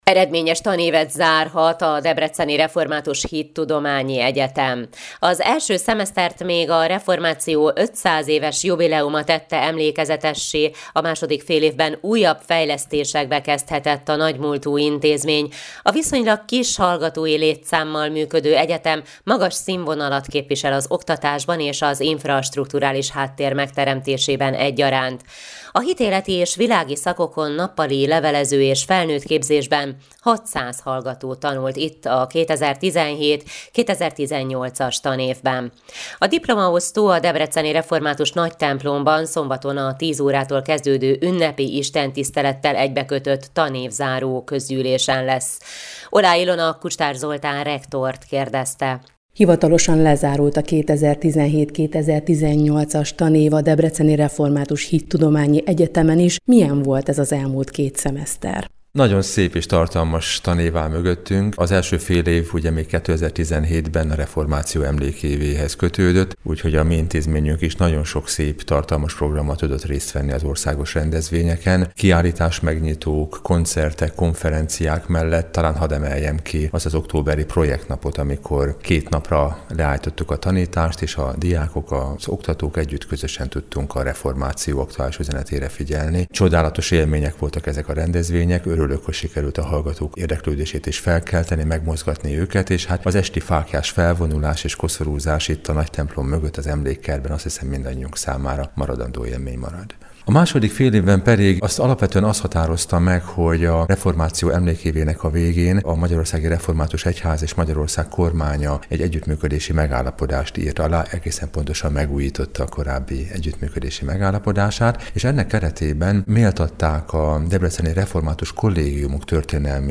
évzáró beszédében